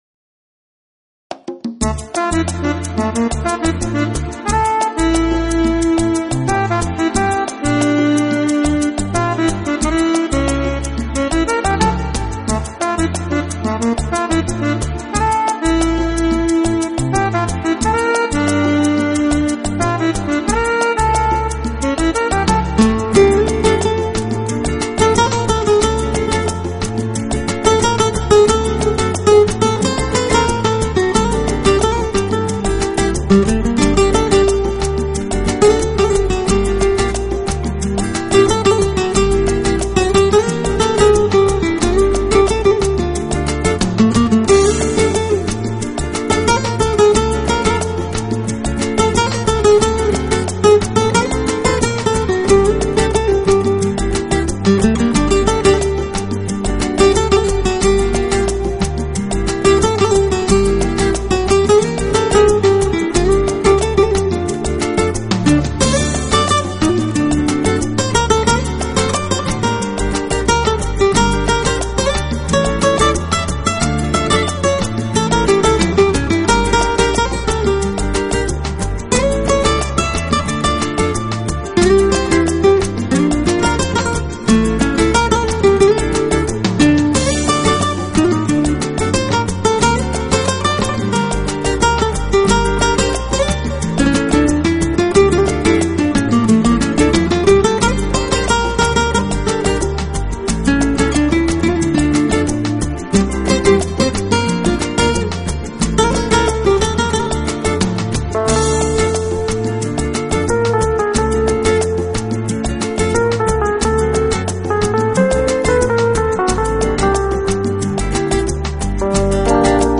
音乐类型：新佛拉蒙哥